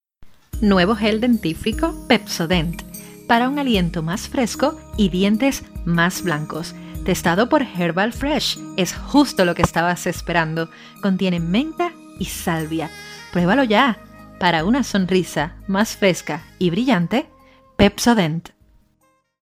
Locutora y narradora Voice over
Sprechprobe: Werbung (Muttersprache):